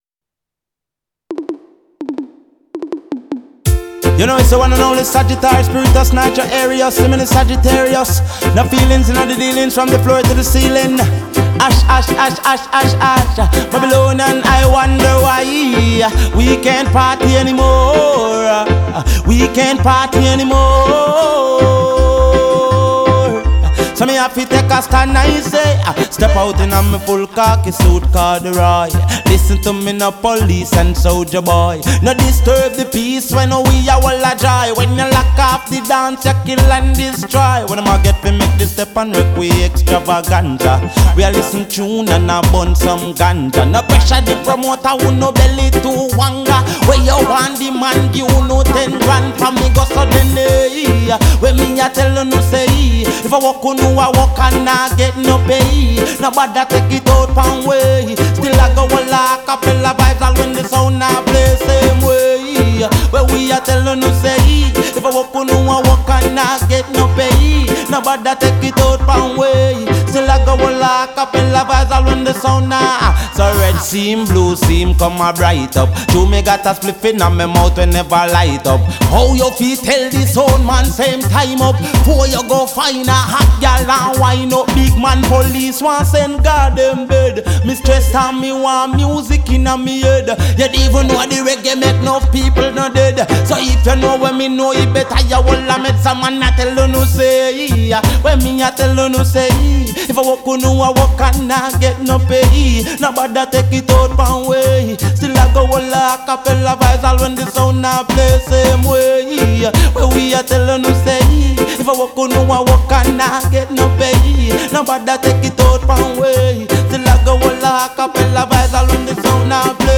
reggae riddim